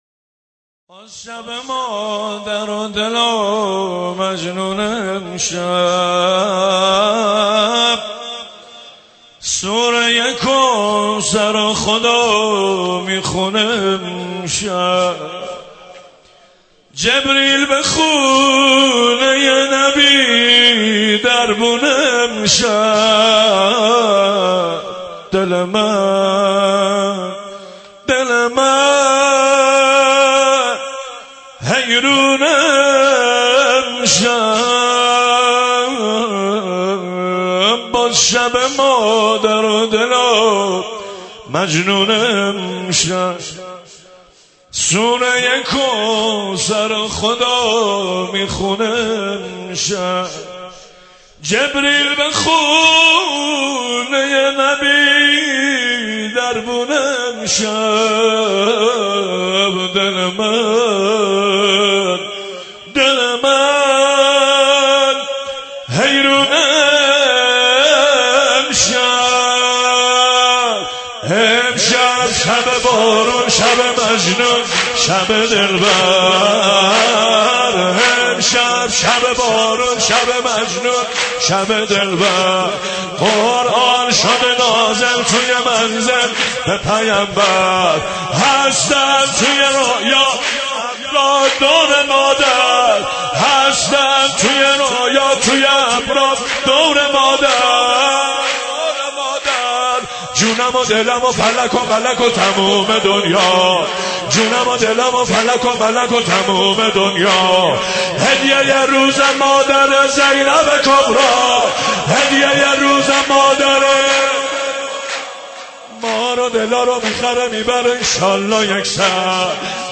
مولودی خوانی
بمناسبت میلاد با سعادت حضرت زهرا سلام الله علیها.